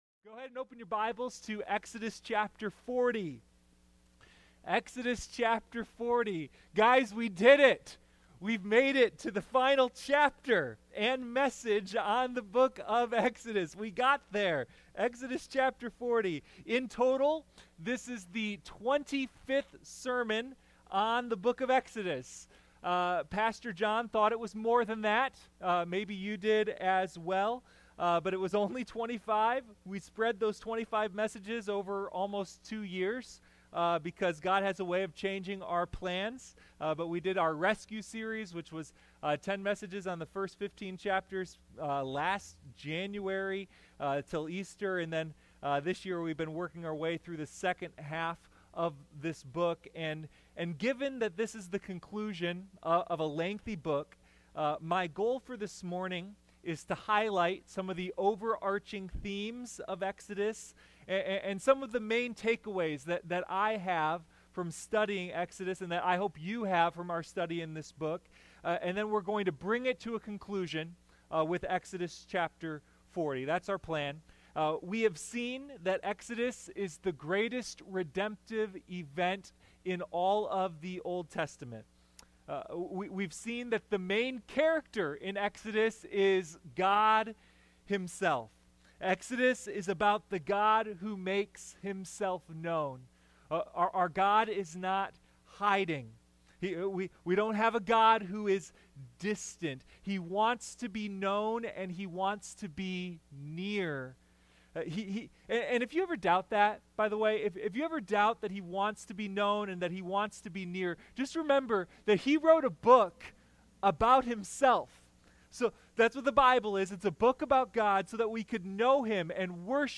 Sunday Morning Communion: A Study in Exodus